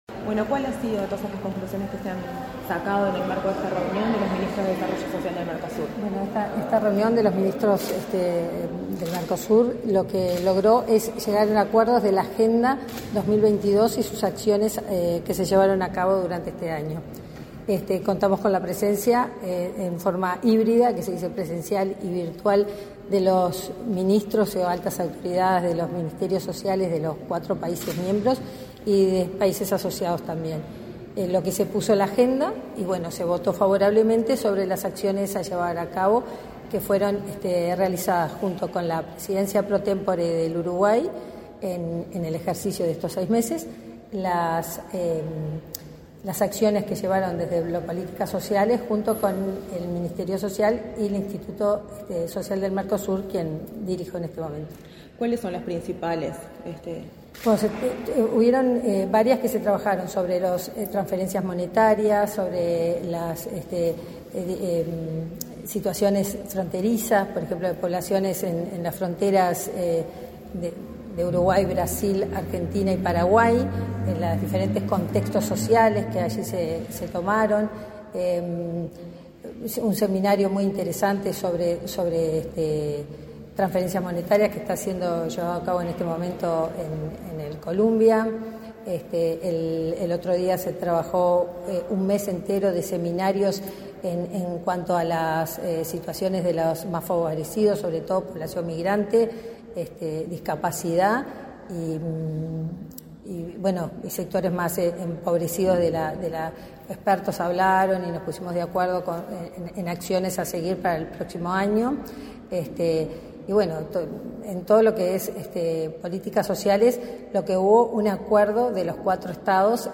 Entrevista a la directora ejecutiva del Instituto Social del Mercosur, Mariana Penadés
Entrevista a la directora ejecutiva del Instituto Social del Mercosur, Mariana Penadés 23/11/2022 Compartir Facebook X Copiar enlace WhatsApp LinkedIn En el marco de la Presidencia temporal uruguaya del Mercosur, se realizó, este 23 de noviembre, la 51.ª Reunión de los Ministros y Autoridades de Desarrollo Social del Mercosur. Tras el evento, la directora ejecutiva del Instituto Social del Mercosur, Mariana Penadés, realizó declaraciones a Comunicación Presidencial.